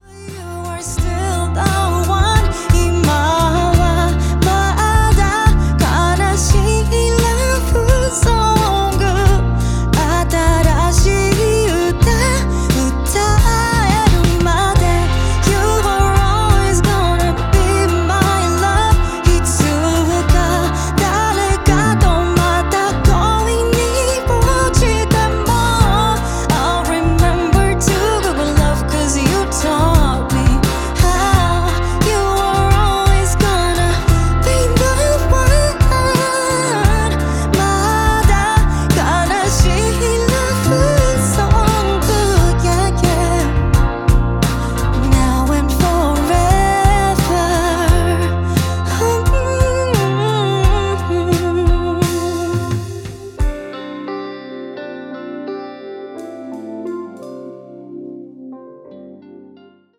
美籍日裔女歌手